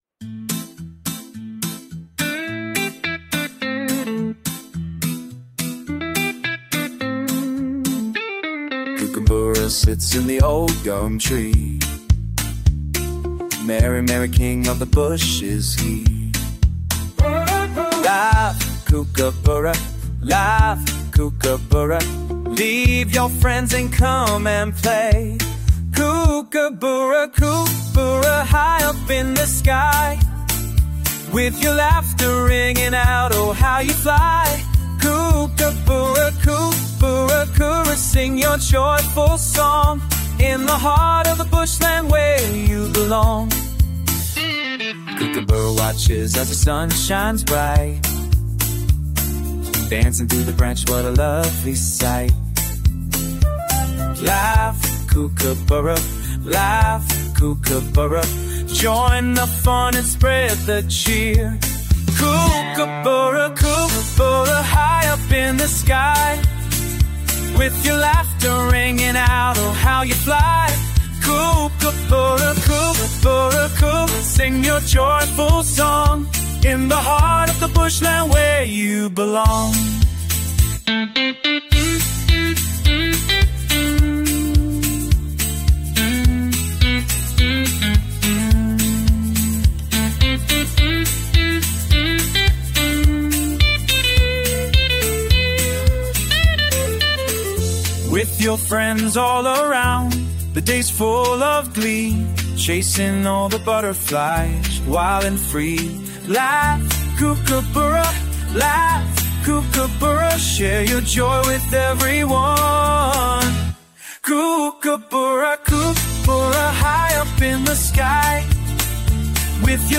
Crazy Chuckle Of Several Kookaburras Mp 3